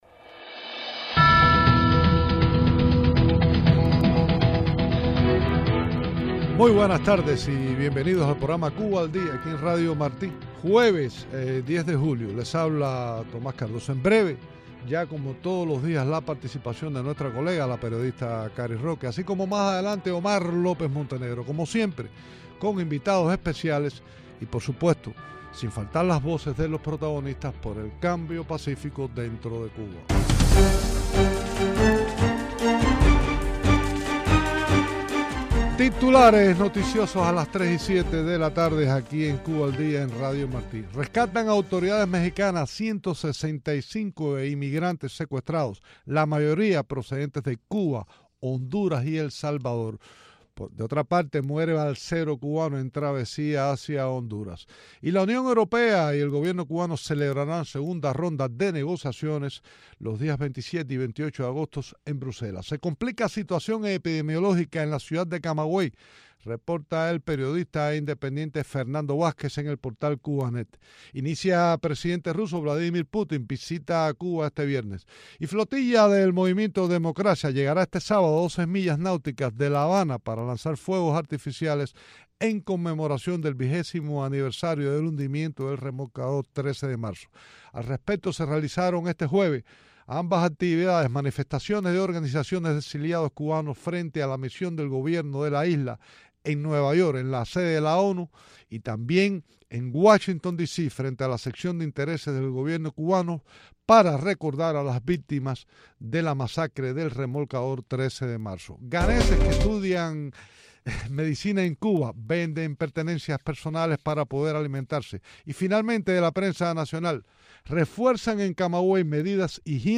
Entrevistas
presenta los titulares del día
de visita en los estudios de Radio Martí